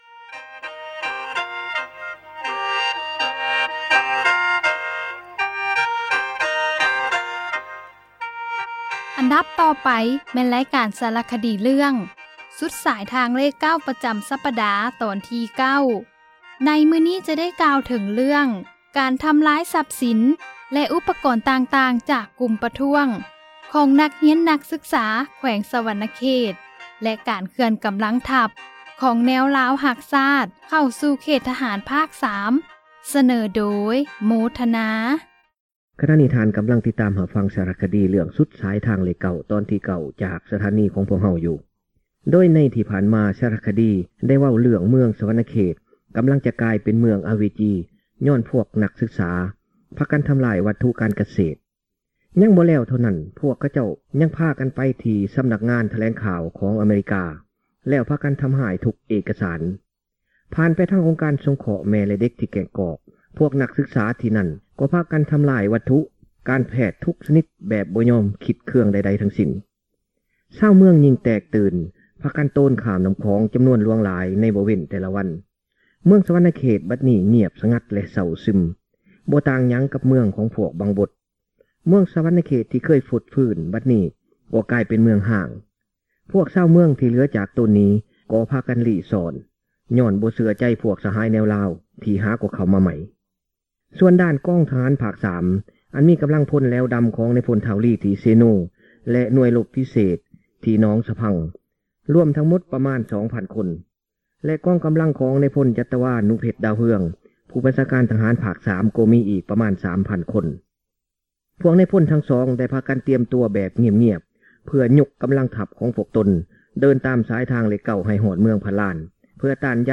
ສາຣະຄະດີ ເຣື້ອງ ສຸດສາຍທາງເລຂ 9 ຕອນທີ 9 ໃນມື້ນີ້ ຈະໄດ້ກ່າວ ເຖິງ ການທຳລາຍ ຊັບສິນ ແລະ ອຸປກອນຕ່າງໆ ຈາກ ກຸ່ມປະທ້ວງ ຂອງ ນັກຮຽນ ນັກສຶກສາ ແຂວງ ສະຫວັນນະເຂດ ແລະ ການເຄື່ອນ ກຳລັງທັບ ຂອງ ແນວລາວ ຮັກຊາດ ເຂົ້າສູ່ເຂດ ທະຫານ ພາກສາມ.